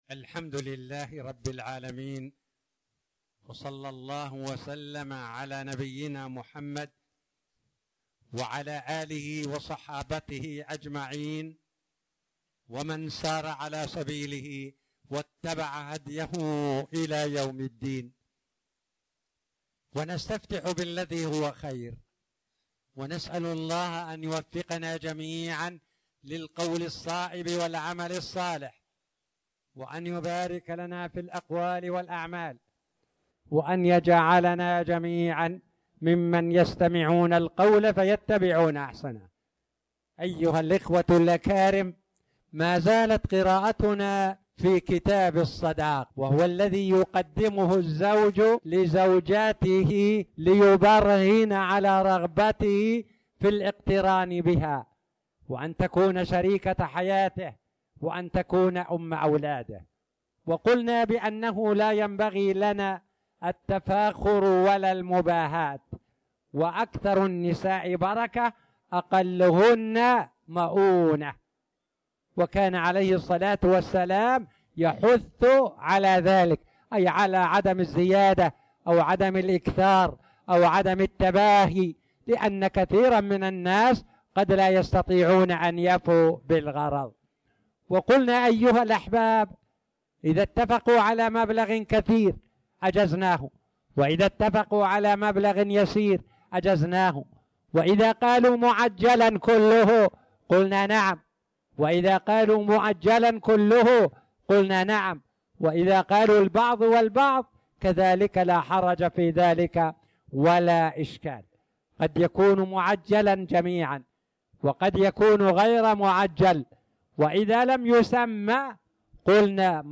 تاريخ النشر ١١ جمادى الأولى ١٤٣٨ هـ المكان: المسجد الحرام الشيخ